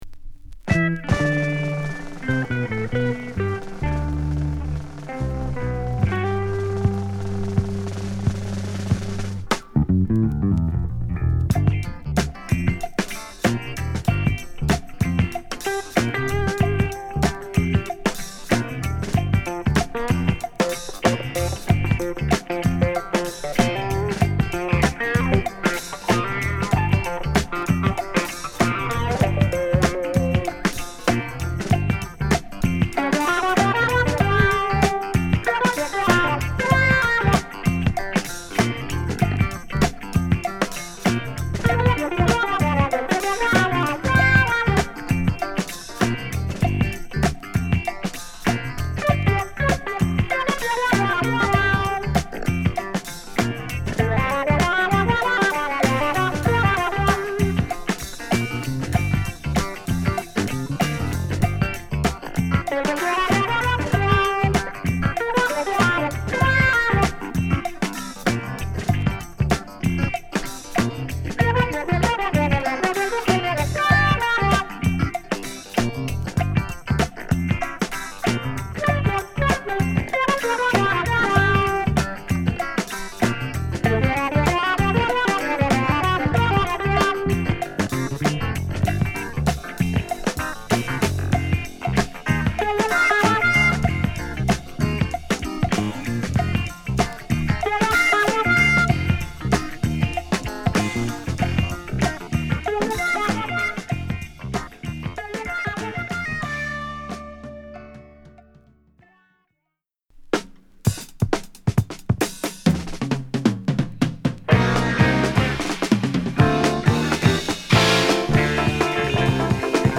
洗練されたサウンドでソウル色濃いナイスなフュージョン～ジャズファンクを収録！